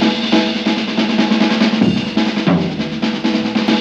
JAZZ BREAK17.wav